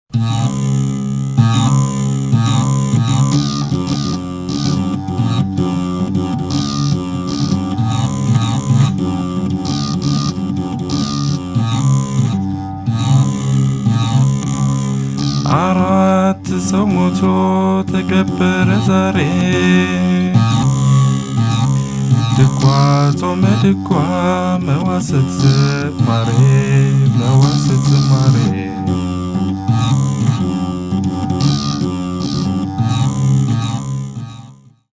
Lyre beganna
Ethiopie-Les-chants-de-Bagana.mp3